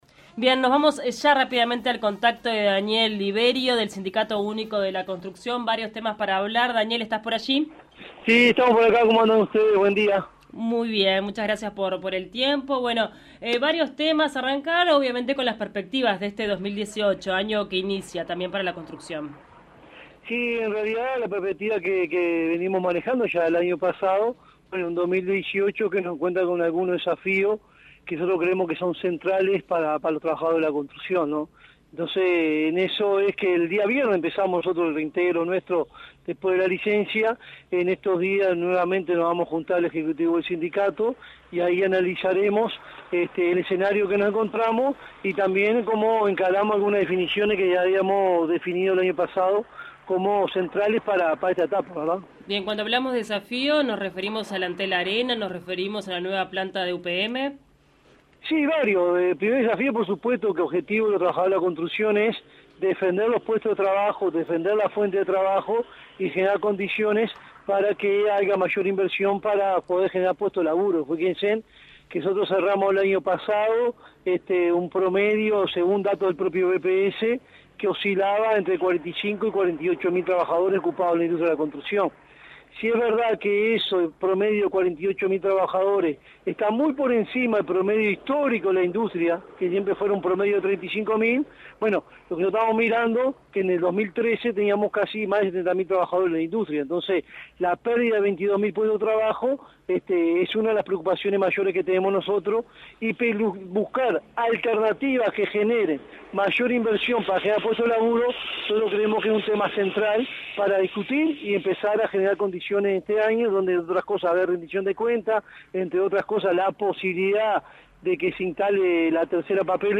Se retoma la actividad en la construcción luego de la licencia anual. Fuentes Confiables realizó un informe especial sobre el sector con las voces de los Empresarios y de los trabajadores.